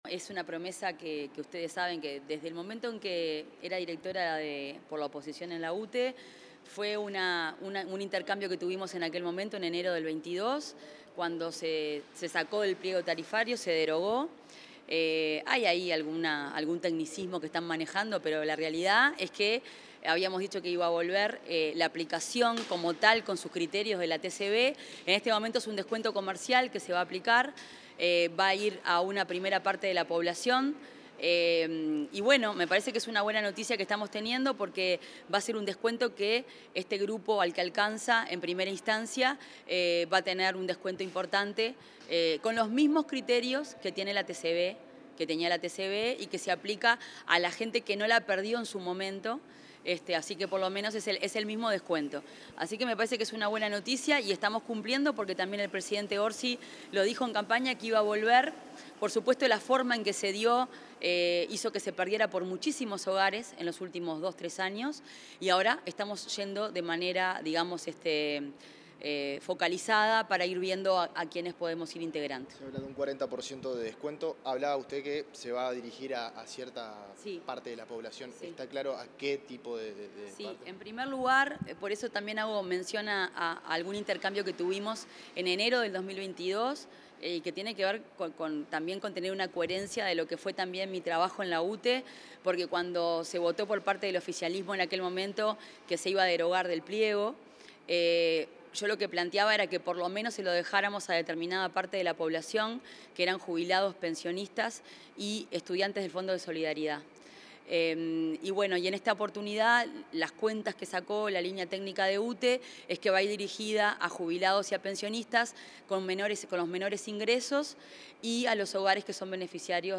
Tras una reunión que mantuvo en la Torre Ejecutiva, la ministra de Industria, Energía y Minería, Fernanda Cardona, efectuó declaraciones a los medios